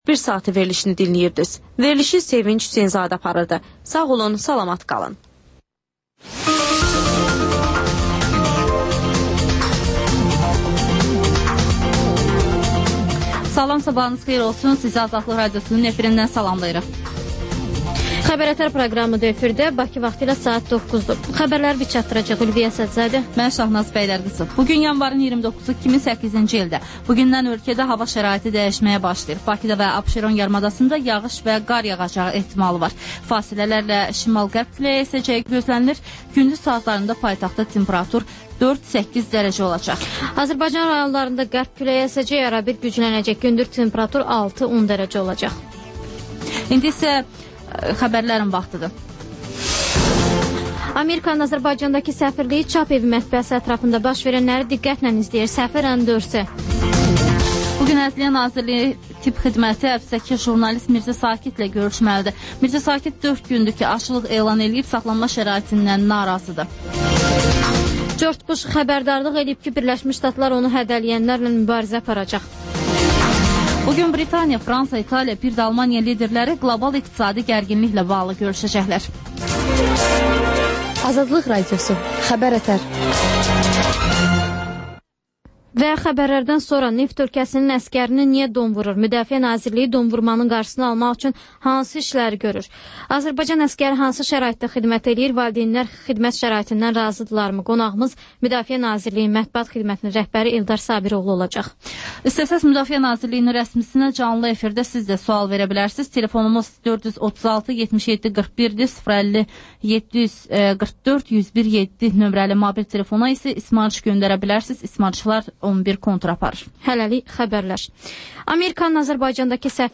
Xəbər-ətər: xəbərlər, müsahibələr, sonra TANINMIŞLAR verilişi: Ölkənin tanınmış simalarıyla söhbət